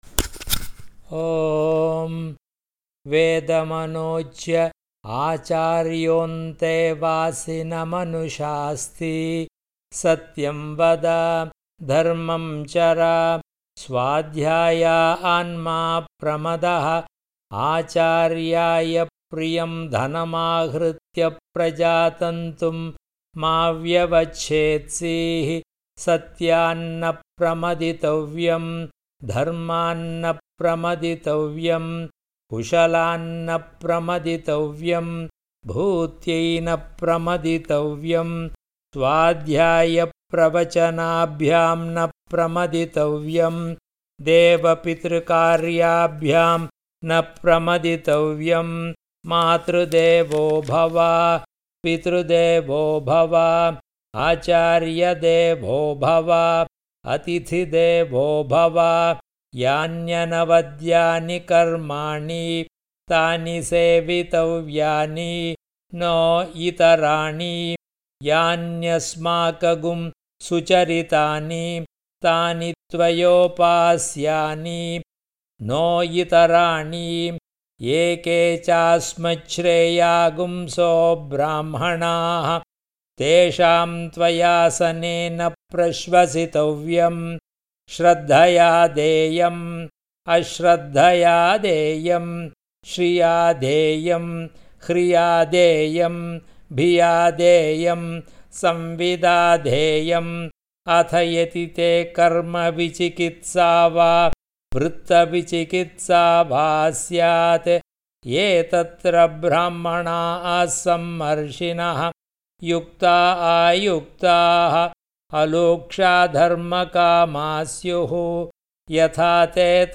Convocation_Mantra_Chant_MP3.mp3